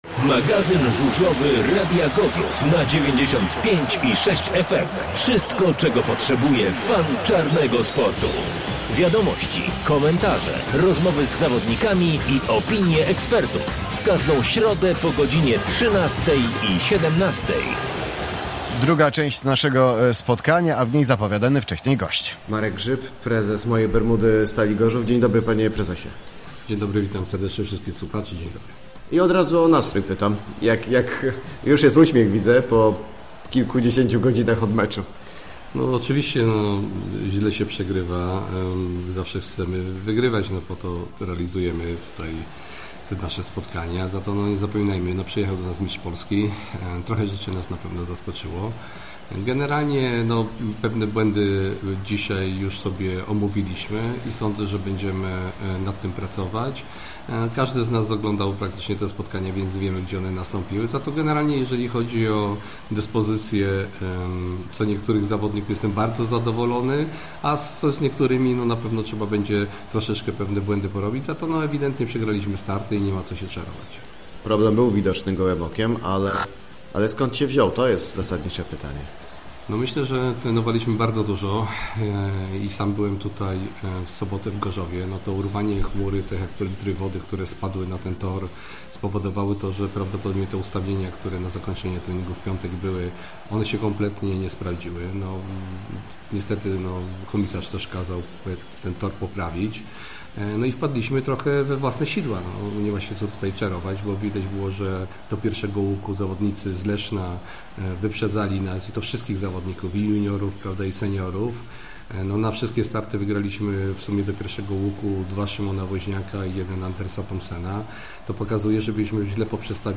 W pierwszej części wypowiedzi zawodników i trenerów